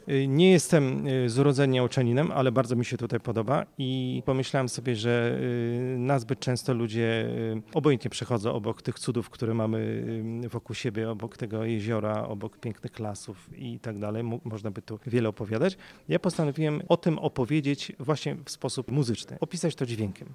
Skąd gitarzysta czerpał inspirację? Opowiedział o tym reporterowi Radia 5.